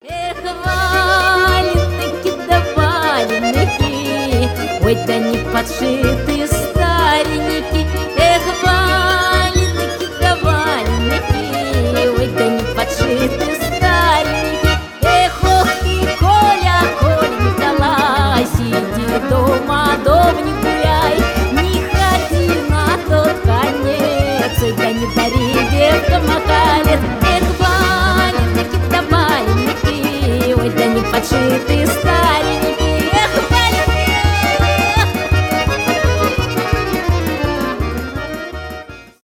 веселые
народные
эстрадные